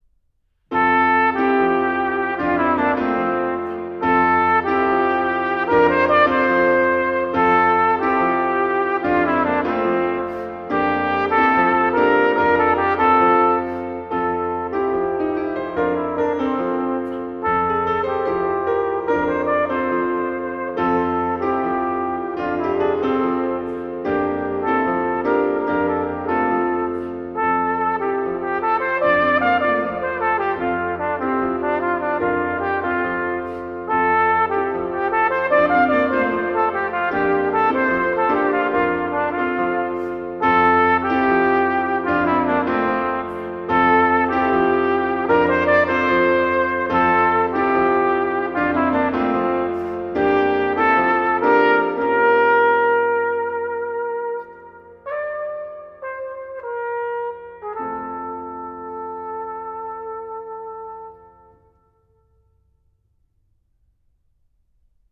Gattung: Trompete und Klavier inkl.